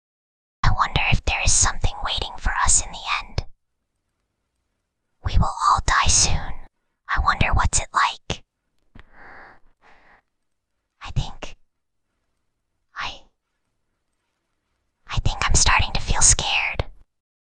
File:Whispering Girl 20.mp3
Whispering_Girl_20.mp3